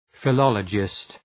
Προφορά
{fı’lɒlədʒıst} (Ουσιαστικό) ● φιλόλογος